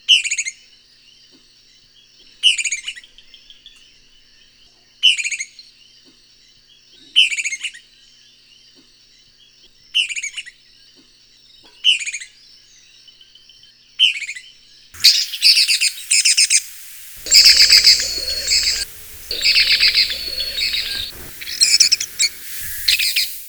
Loggerhead Kingbird
Tyrannus caudifasciatus
VOICE: A loud, bubbly, "pi ti tity", but outside the breeding season, it vocalizes less often than the Gray Kingbird.
Like the Gray Kingbird, members of a pair will often greet each other just as one member of the pair returns to a perch near the other.